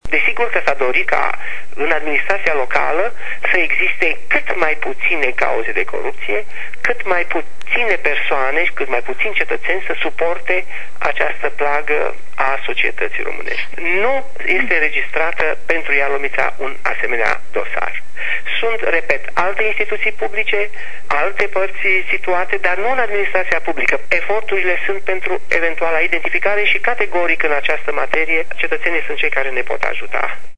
Prim procurorul Parchetului de pe langa Tribunalul Ialomita, Ioan Rasnoveanu spune ca pana la aceasta data in Ialomita nu se inregistreaza nici un caz  de coruptie in administratia publica si ca pentru identificarea situatiilor este nevoie de ajutorul cetatenilor care sa semnaleze astfel de fapte: